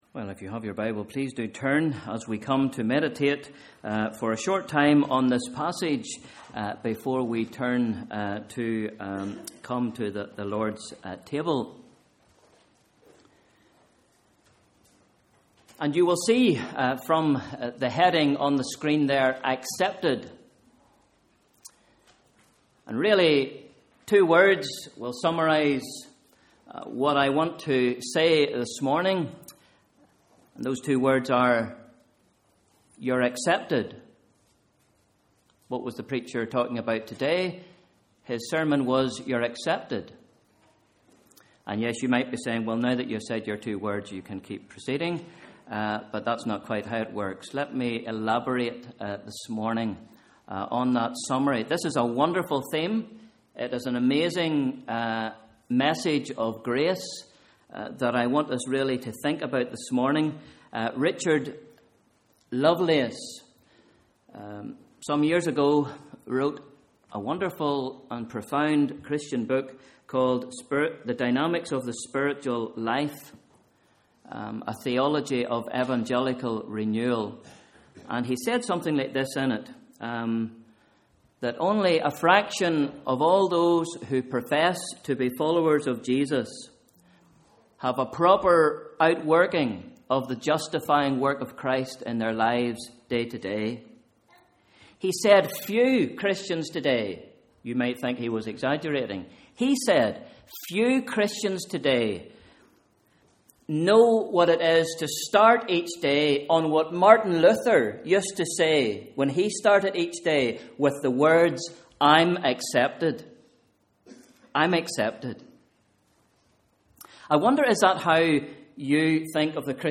Morning Service: Sunday 29th September 2013 / Bible Reading: Romans 3 v 19-26